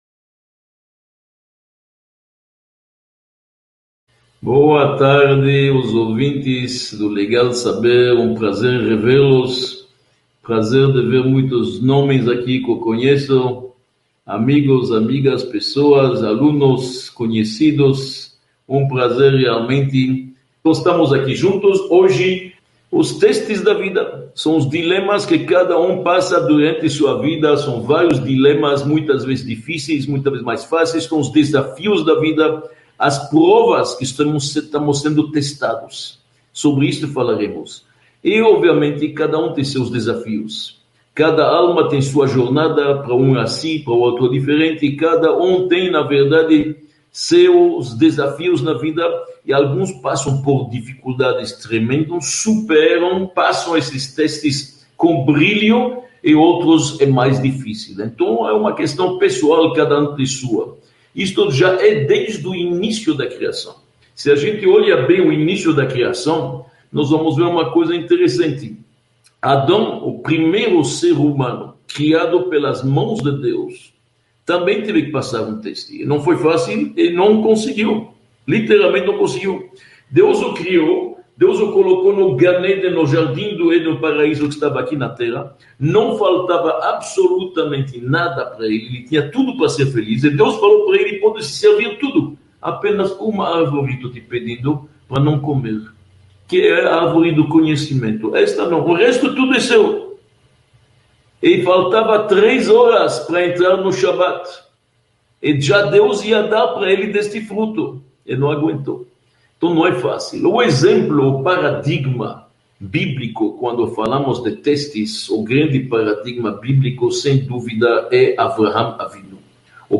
02 – As provas da vida | Um olhar para dentro de nós – Aula 02 | Manual Judaico